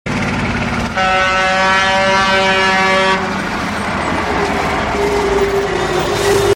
HONKING IN HEAVY TRAFFIC.mp3
Traffic jam causing drivers to loose control and generate big sound pollution.
honking_in_heavy_traffic_egl.ogg